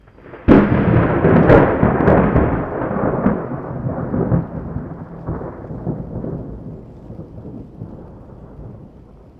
thunder-6.mp3